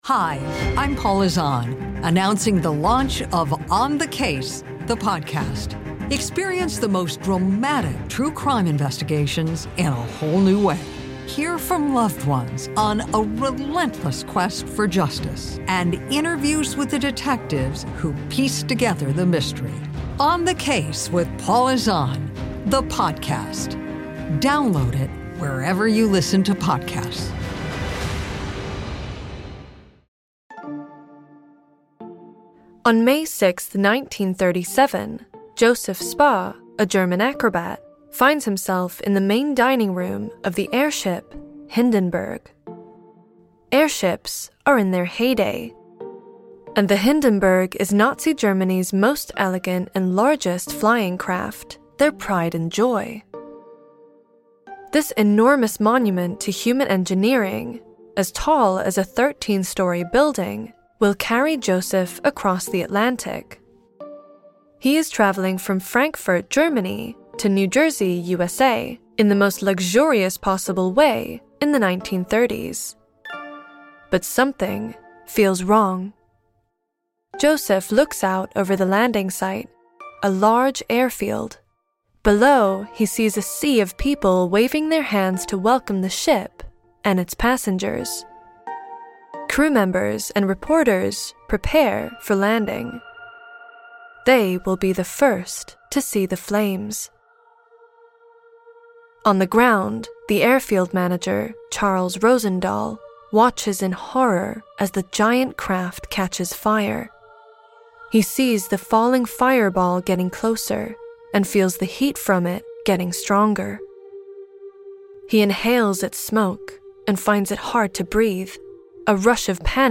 On May 6th 1937, radio listeners in New Jersey got the broadcast of a lifetime. They are listening to presenter Herbert Morrison describe the landing of the Hindenburg zeppelin, and at first, everything sounds like it’s going to plan. But the tone shifts, and a panicked Morrsion describes how the airship has caught on fire.